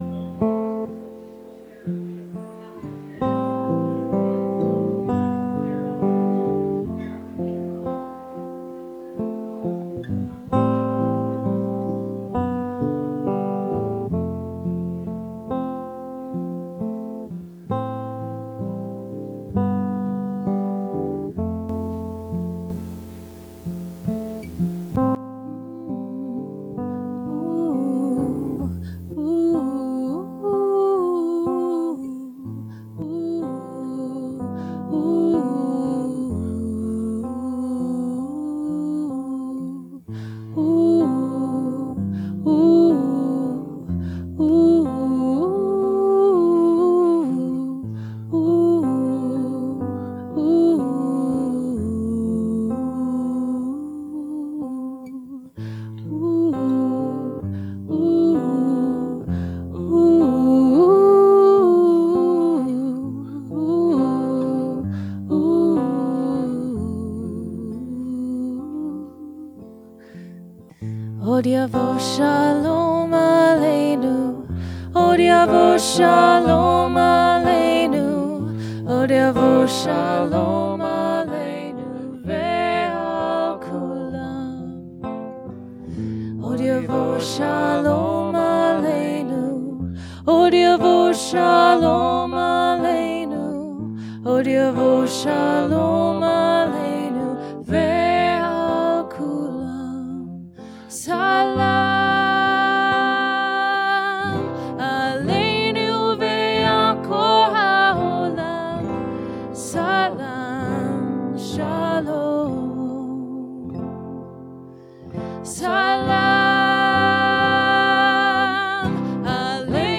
The next afternoon, October 28th, here in Bozeman, an interfaith group of local citizens came together for a solidarity gathering at Congregation Beth Shalom, to demonstrate support for the Bozeman Jewish Community, as well as to comfort one another after a shocking week of violence, and the threat of violence, and to come together in peace.
2-Beth-Shalom-Gathering-ed.mp3